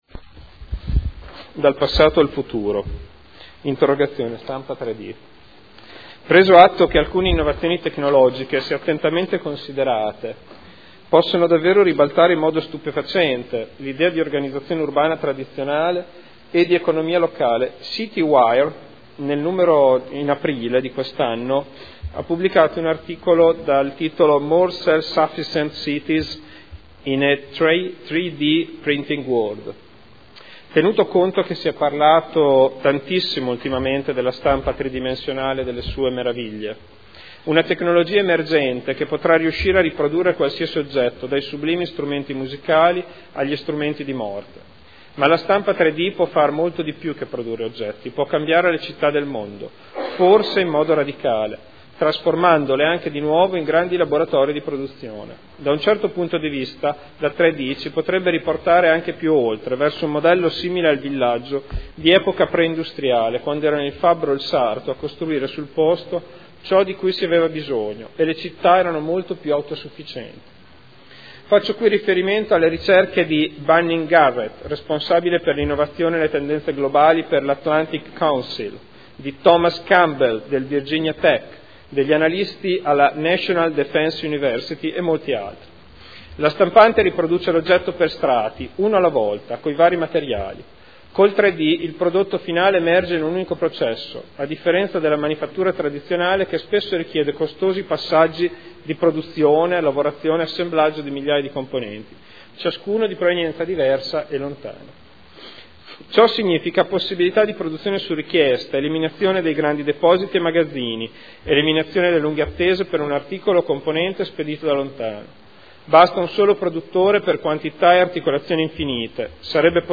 Seduta del 20/05/2013. Interrogazione del consigliere Ricci (SEL) avente per oggetto: “Stampa 3D”